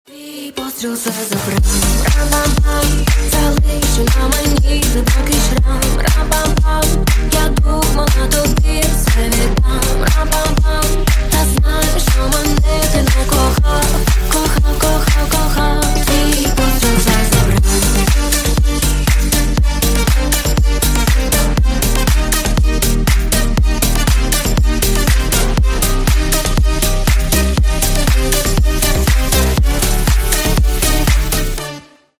Кавер И Пародийные Рингтоны
Танцевальные Рингтоны